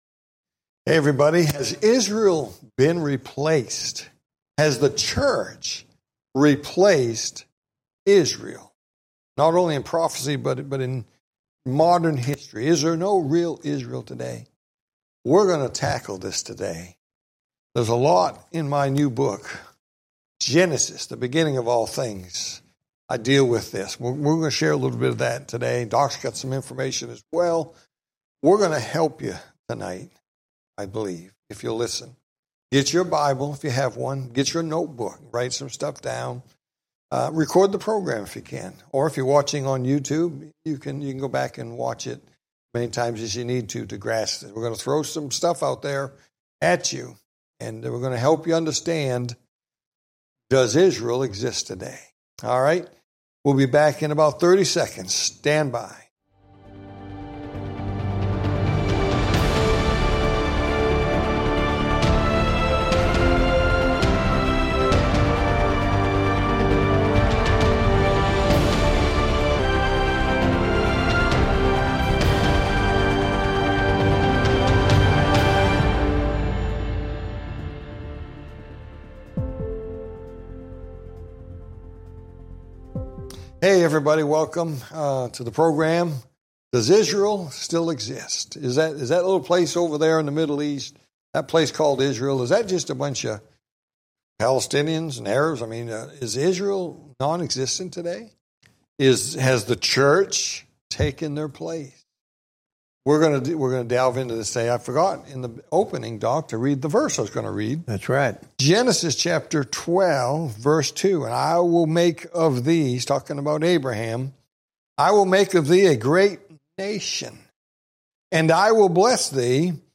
Talk Show Episode, Audio Podcast, Prophecy In The Spotlight and Has Israel Been Replaced on , show guests , about Has Israel Been Replaced, categorized as History,News,Politics & Government,Religion,Society and Culture,Theory & Conspiracy